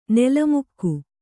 ♪ nela mukku